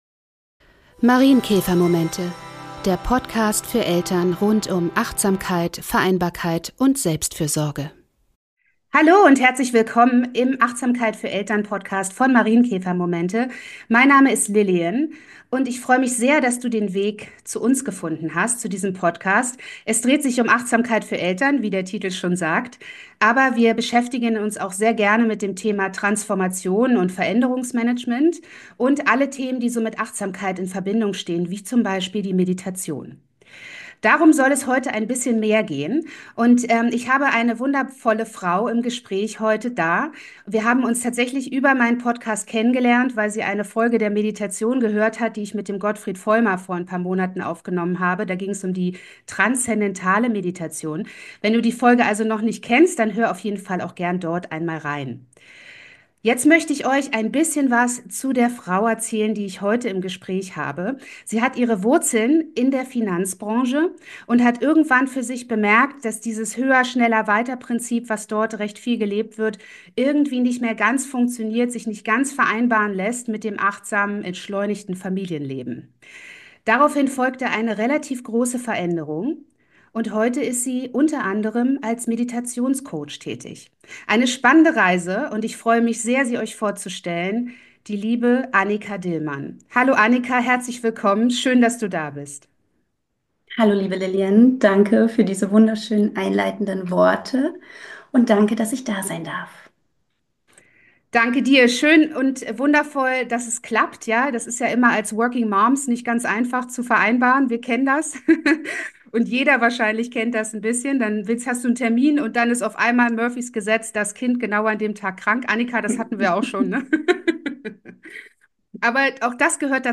Ein spannendes Podcast-Gespräch
Sie leitet eine Meditation für uns an, sodass wir in ihre Arbeit rein schnuppern können und ein Live Erlebnis erhalten, und wir sprechen über Traumreisen - auch für Kinder.